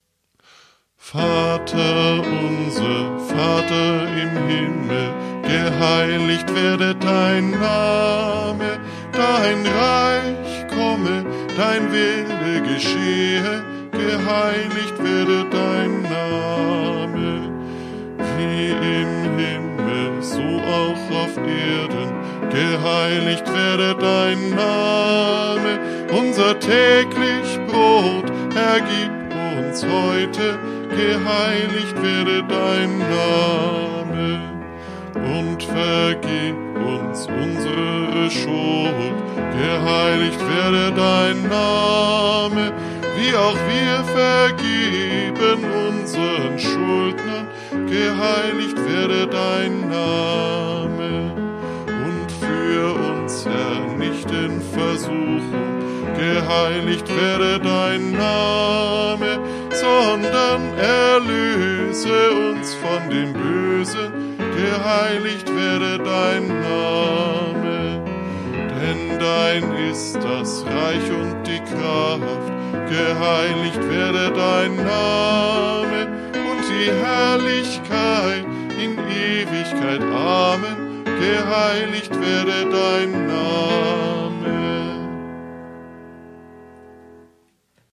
Kinderlieder
EG_188_Vater_unser__Calypso_.mp3